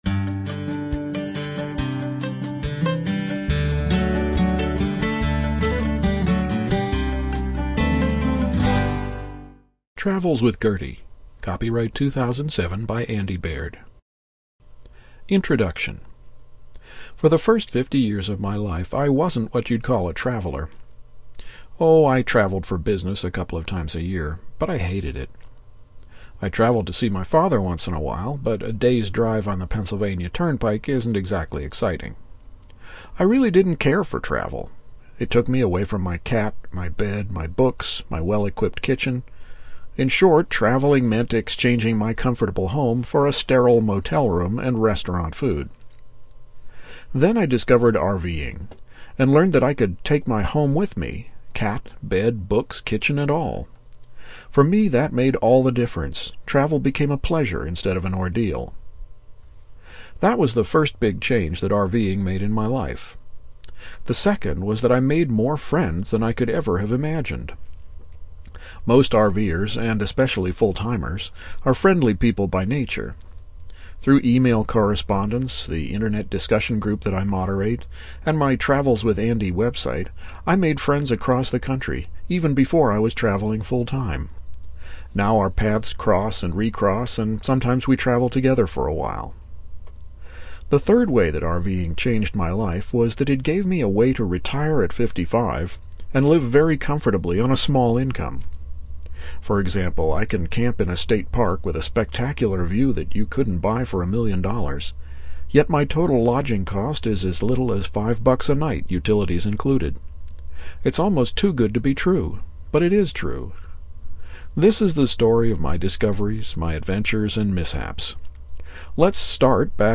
Having spent the summer of 2008 working on the massively updated Eureka 2, I'm planning to spend the spring of 2009 working on a book version of "Travels with Gertie" with lots of photos. And I want to release a matching audiobook at about the same time.